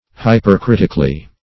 Hypercritically \Hy`per*crit"ic*al*ly\, adv. In a hypercritical manner.
hypercritically.mp3